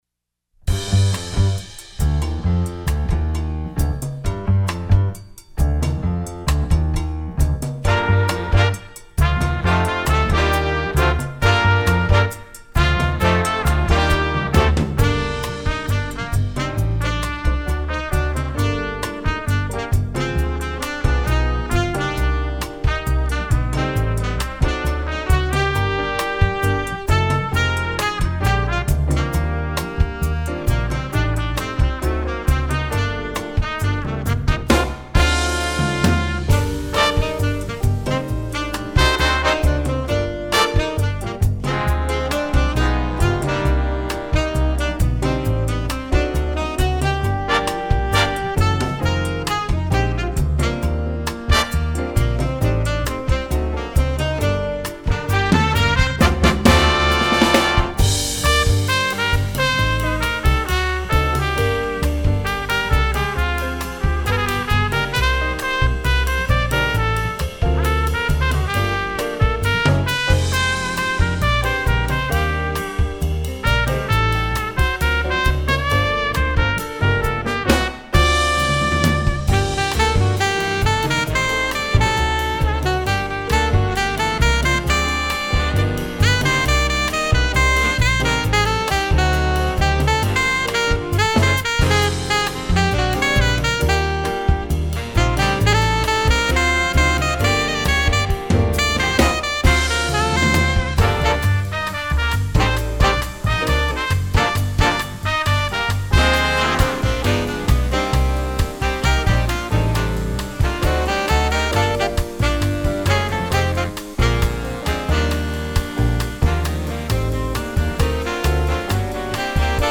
Instrumentation: jazz band
jazz, latin